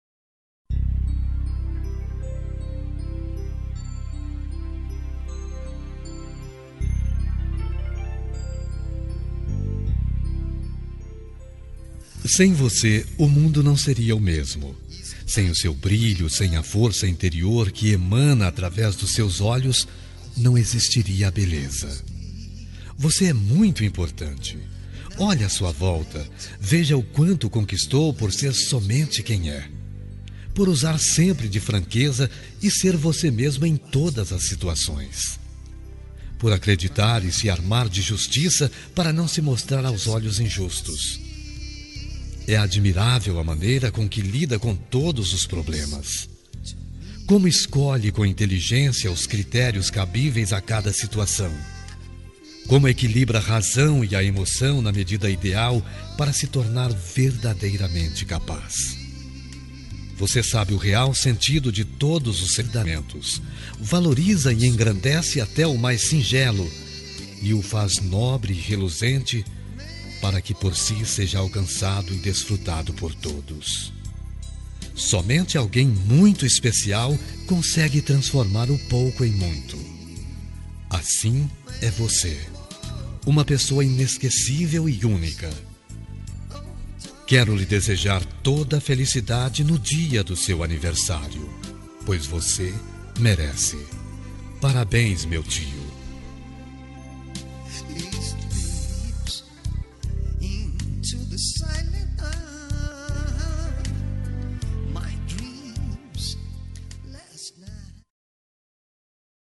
Aniversário de Tio – Voz Masculina – Cód: 917